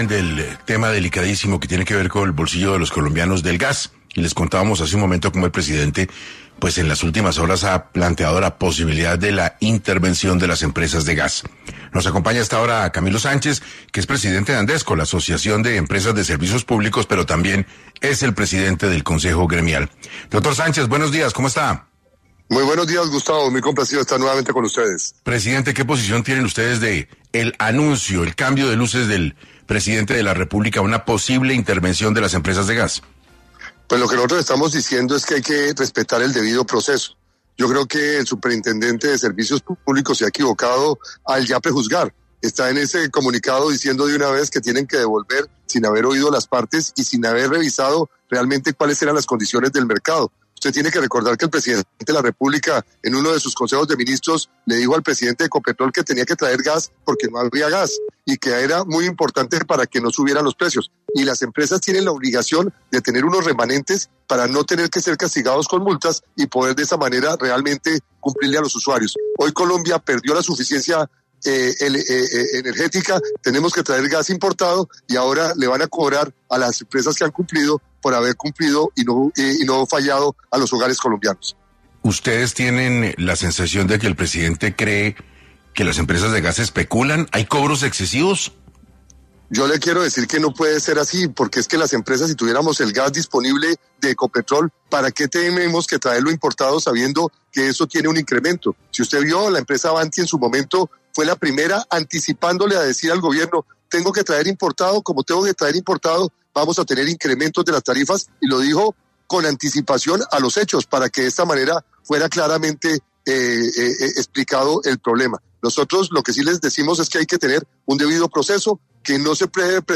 En entrevista para 6AM